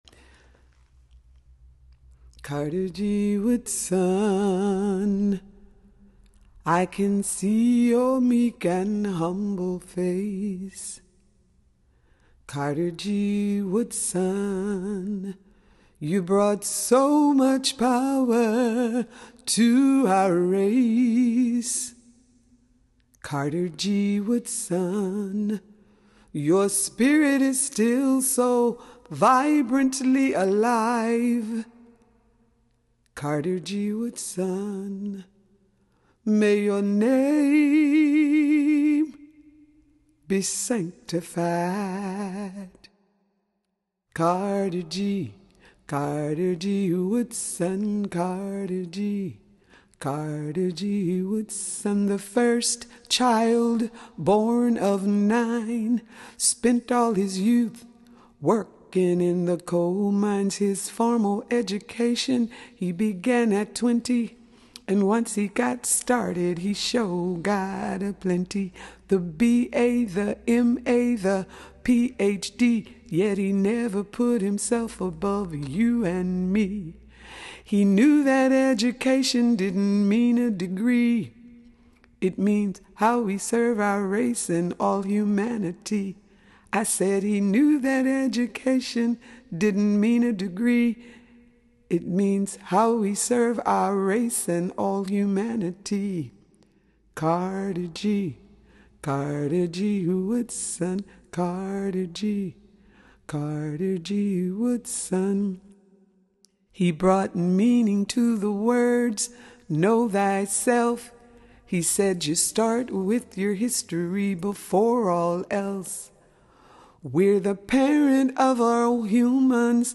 For this Black History Month, please enjoy her song commemorating Carter J. Woodson: